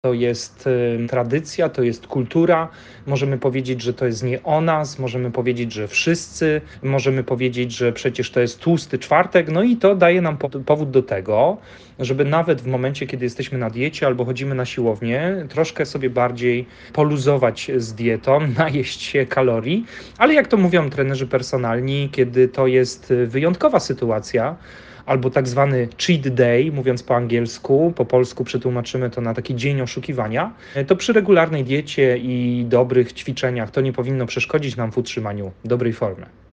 Na te pytania odpowiada trener mentalny